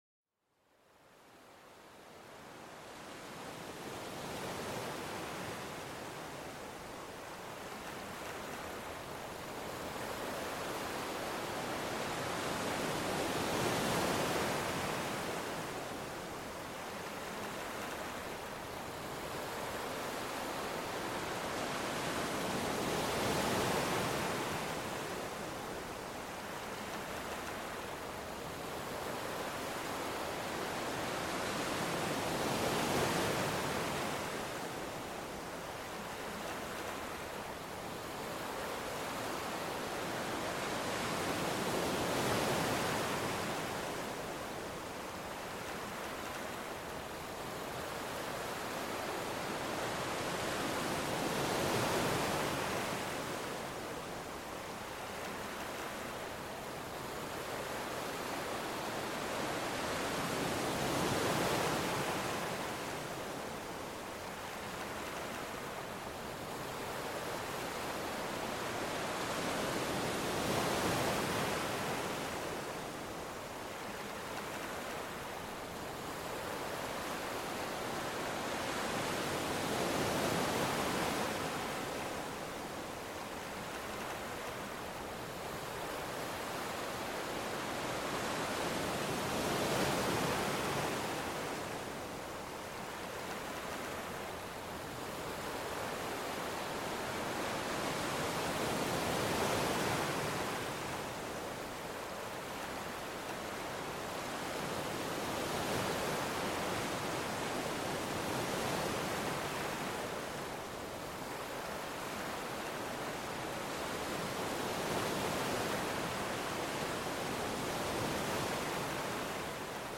Sumérgete en el universo cautivador del sonido de las olas, una sinfonía natural que apacigua el alma. Déjate mecer por el ritmo regular y reconfortante del océano, un verdadero bálsamo para la mente.